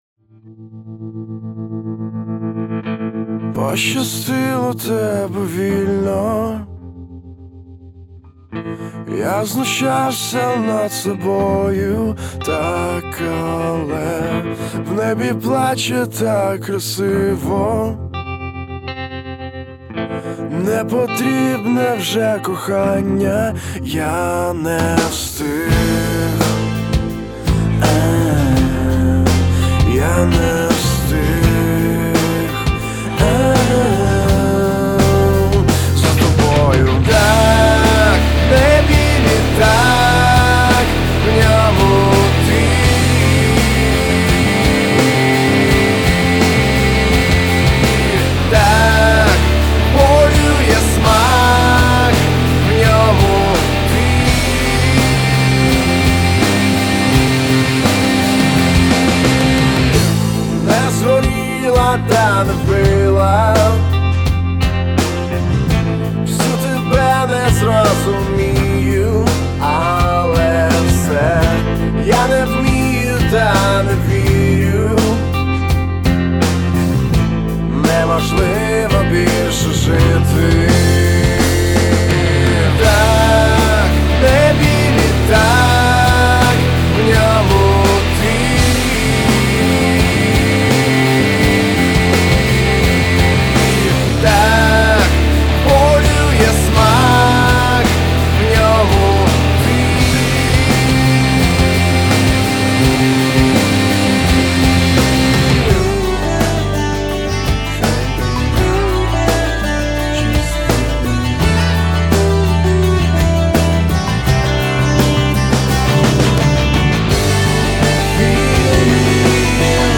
вокал, гитара
барабаны
бас
Играют рок,поп, гранж.